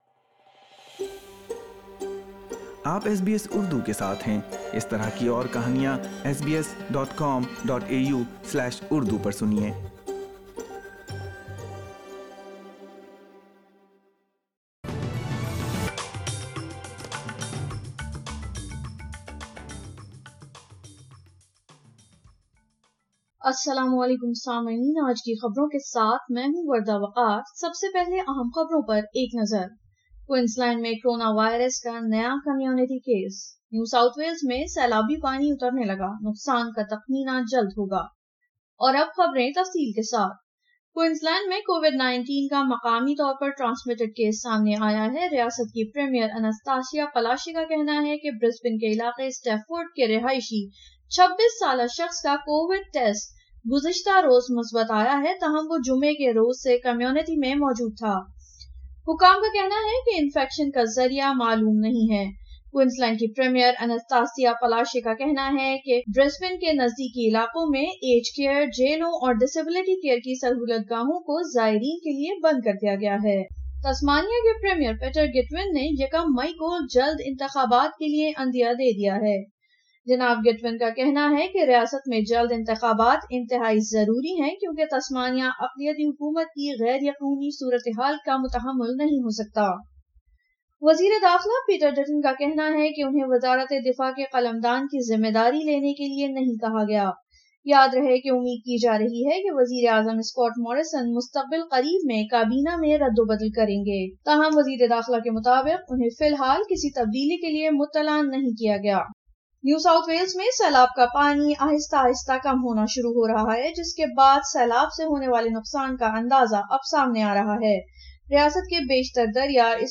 اردو خبریں 26 مارچ 2021